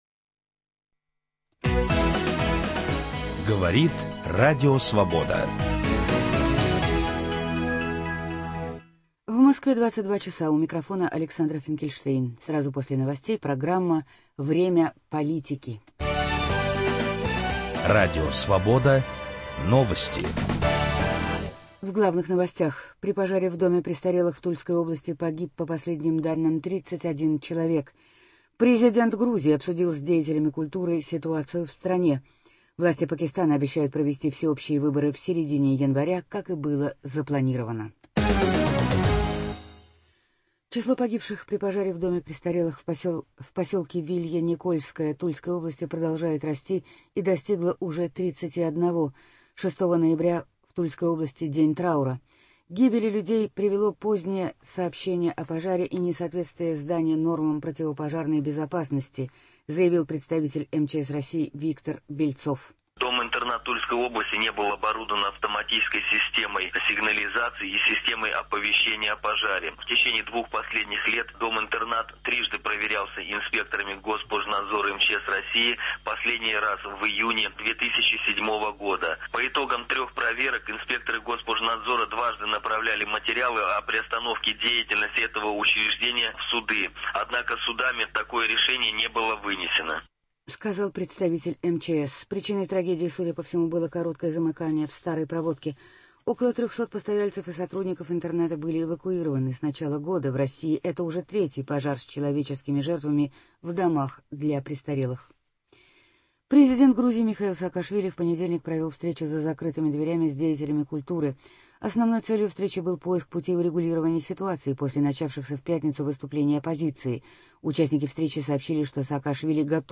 Специальный репортаж о выборах в Краснодарском крае. Выборы Госсовета Удмуртии.